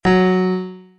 Piano Keys C Scale New